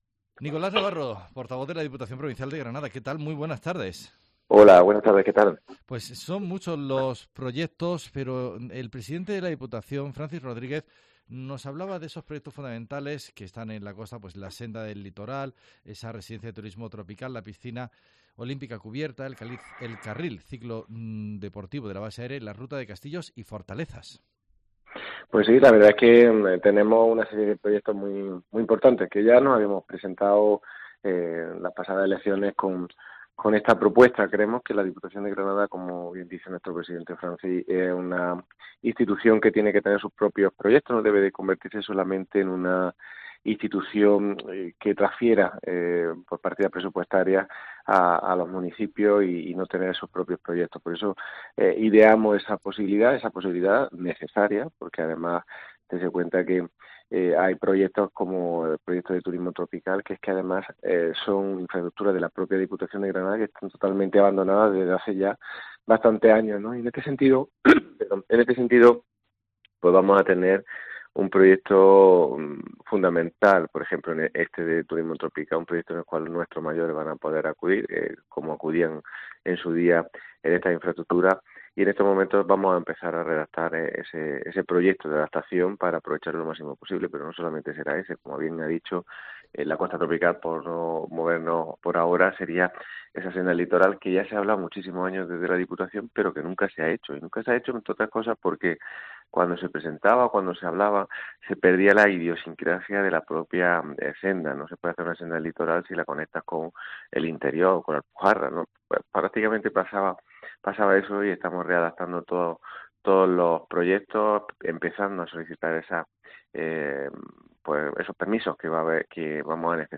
El portavoz del gobierno provincial nos habla de los principales proyectos que quiere impulsar el Gobierno de la Diputación en los próximos meses
Nicolás Navarro, Portavoz del Gobierno de la Diputación